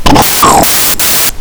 ship_explosion2.wav